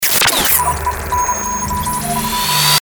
FX-1648-SCROLLER
FX-1648-SCROLLER.mp3